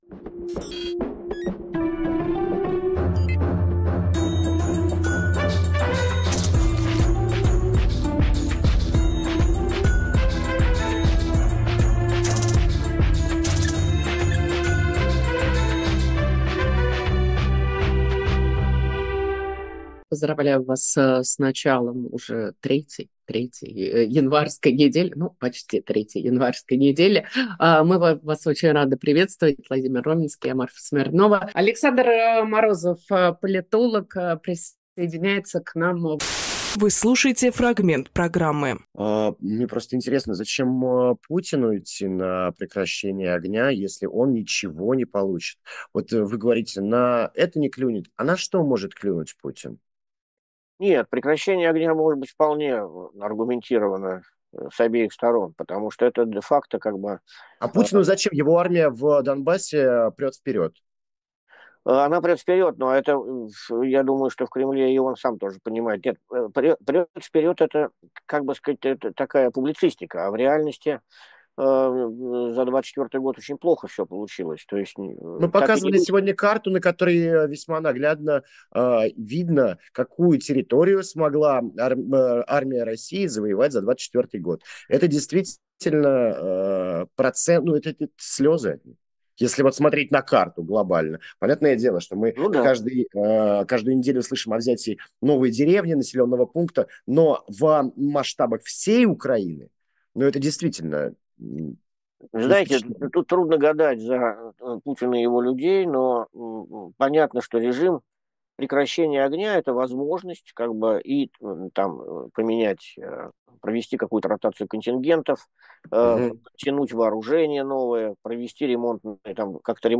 Фрагмент эфира от 20 января.